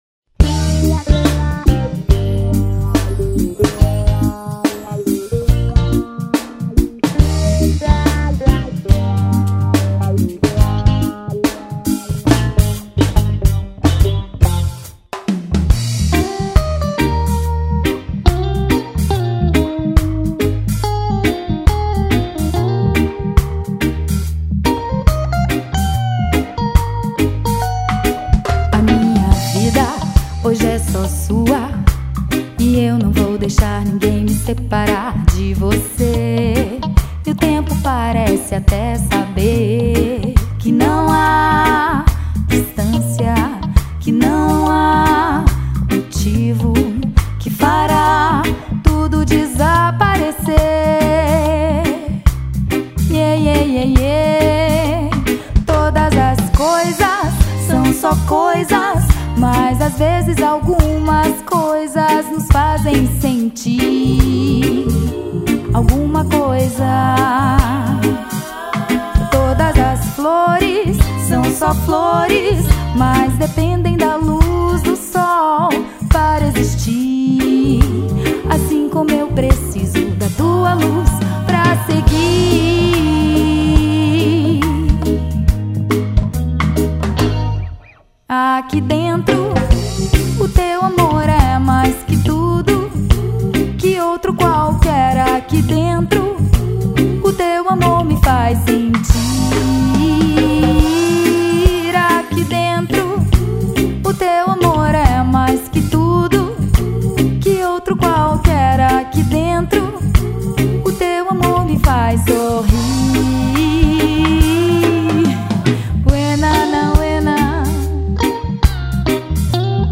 EstiloReggae